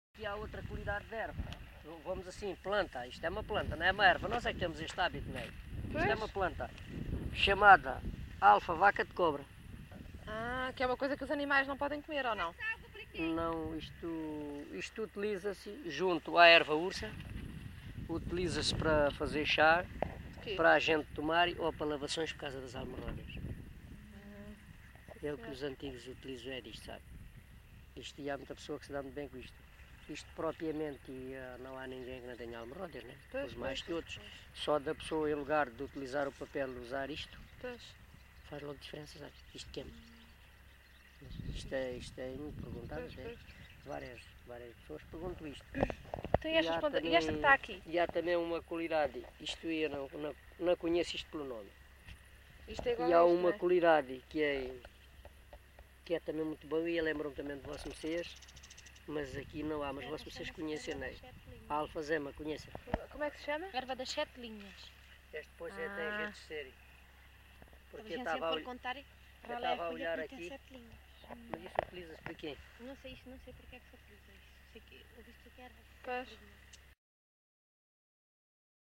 LocalidadeMelides (Grândola, Setúbal)